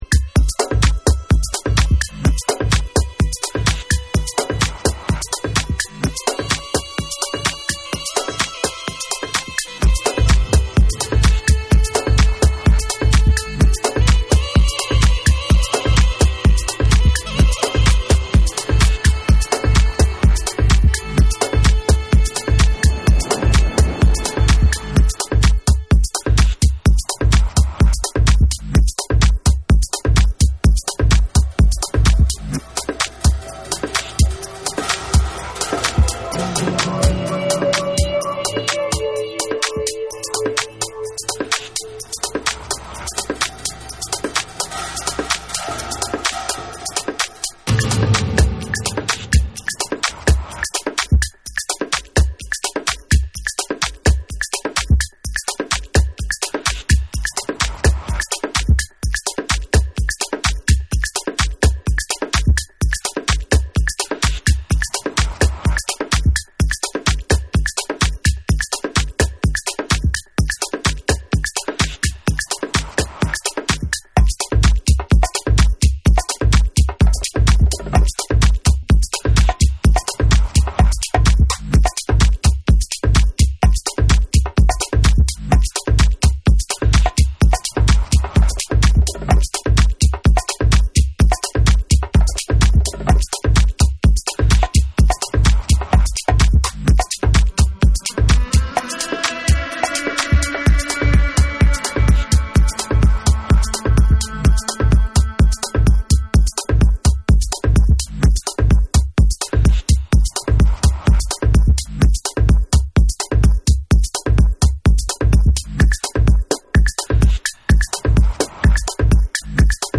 こちらも同じくパーカッションを絡めたバウンシー・トラックにオリエンタル風味な絡み合う
TECHNO & HOUSE / ORGANIC GROOVE